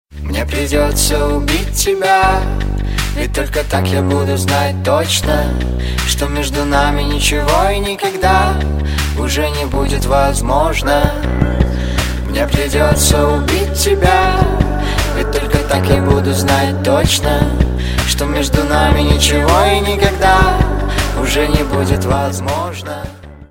• Качество: 320, Stereo
грустные
дуэт
indie pop
alternative
печальные
мужской и женский вокал